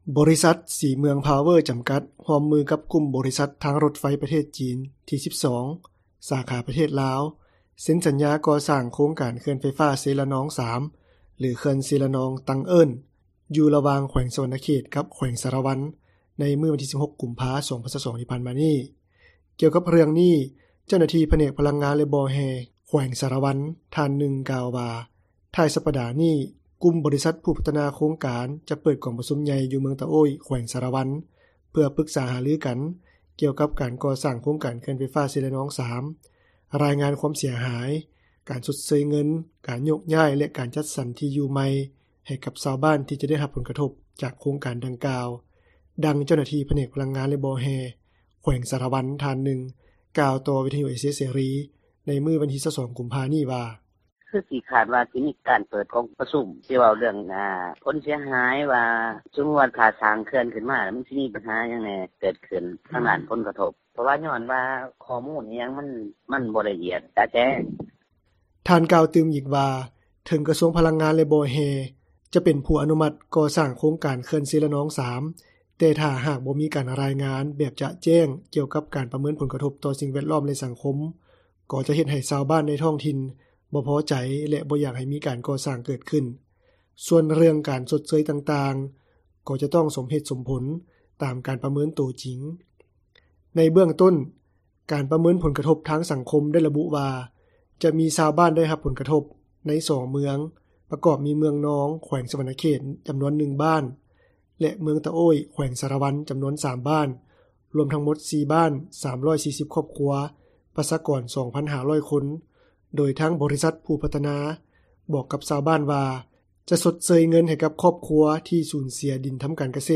ດັ່ງຊາວບ້ານຜູ້ນຶ່ງ ໃນເມືອງຕະໂອ້ຍ ແຂວງສາຣະວັນ ກ່າວຕໍ່ວິທຍຸເອເຊັຽເສຣີ ໃນມື້ວັນທີ 22 ກຸມພາ ນີ້ວ່າ:
ດັ່ງຊາວບ້ານອີກຜູ້ນຶ່ງ ໃນເມືອງຕະໂອ້ຍ ແຂວງສາຣະວັນ ກ່າວຕໍ່ວິທຍຸເອເຊັຽເສຣີ ໃນມື້ວັນທີ 22 ກຸມພາ ນີ້ວ່າ: